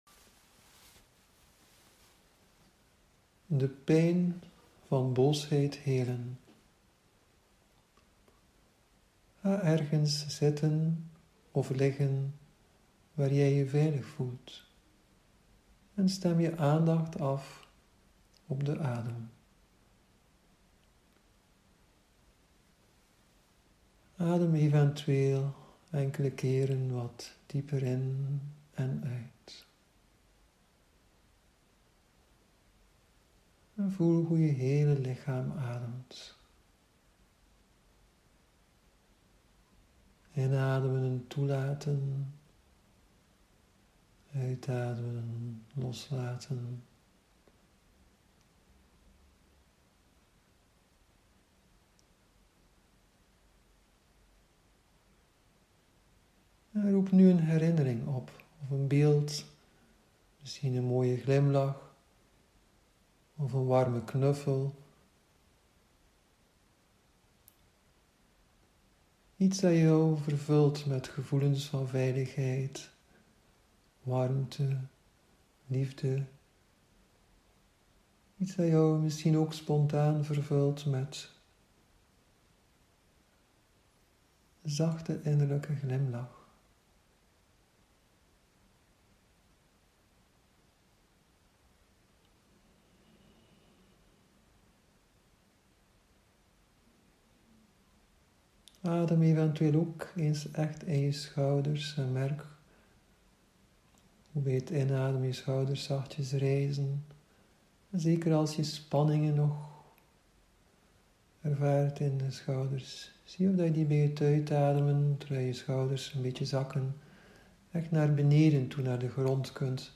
Gratis meditaties bij het Mindfulness werkboek<